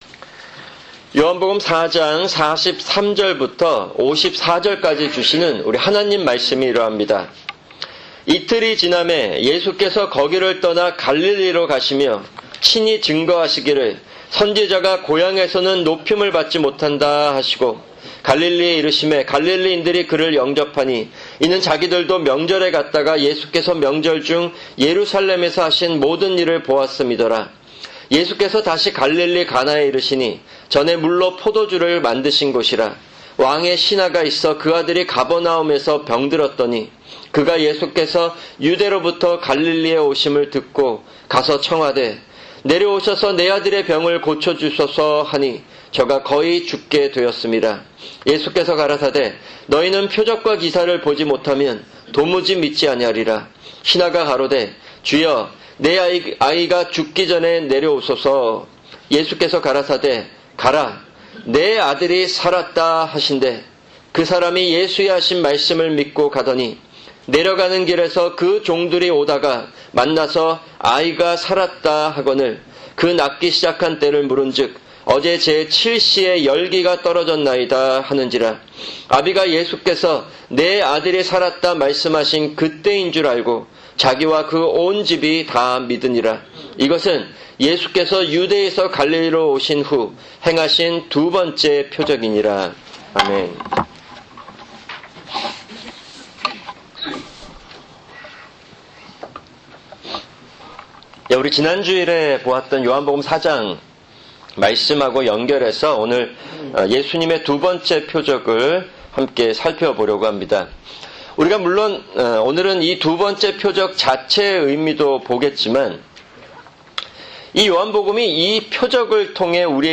[주일 설교] 요한복음 4:43-54